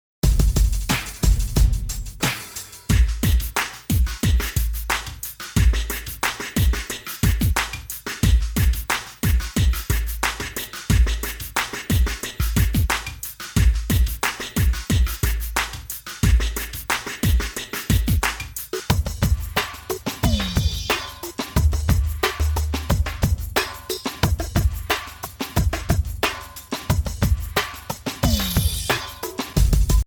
Mp3 Instrumental Song Track